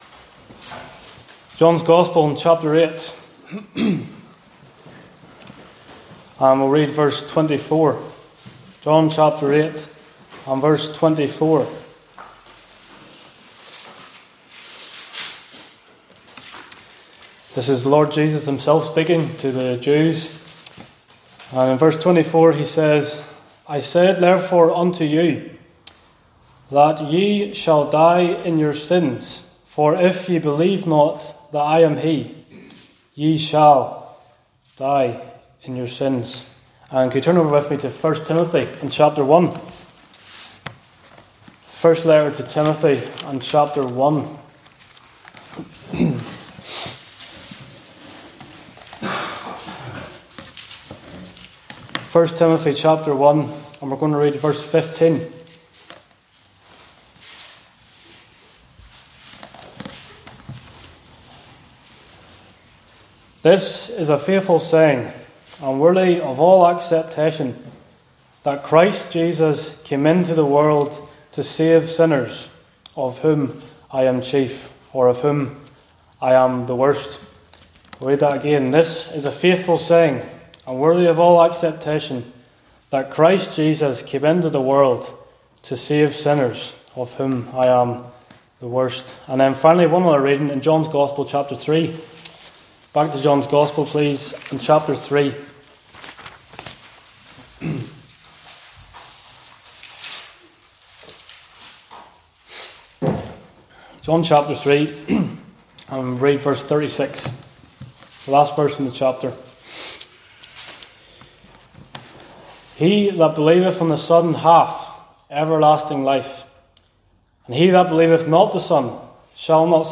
preaches from select New Testament passages that present the gospel as a message of highest importance. We learn from them that if we remain in our sins, then eternal death is inevitable. Thankfully, we also learn that whoever has Jesus Christ as their Lord and Saviour has eternal life and eternal forgiveness.